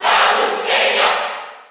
File:Palutena Cheer NTSC SSB4.ogg
Palutena_Cheer_NTSC_SSB4.ogg.mp3